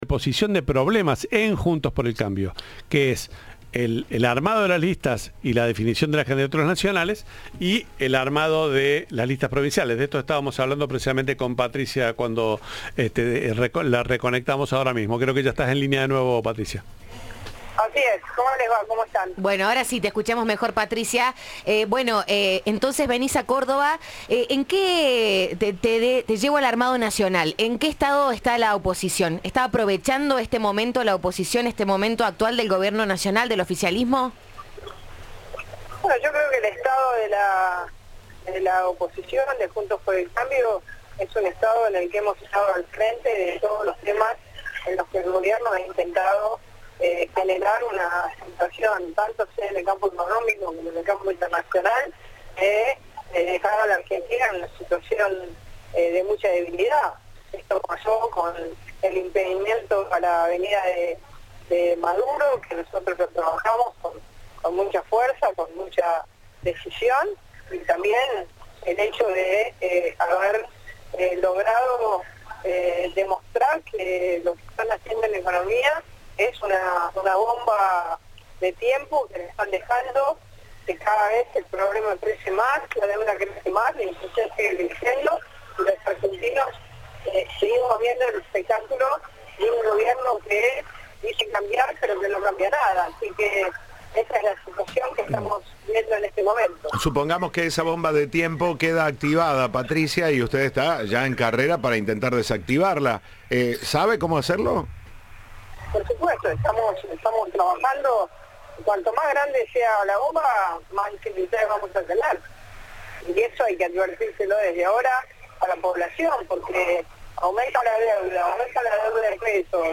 Entrevista de Siempre Juntos.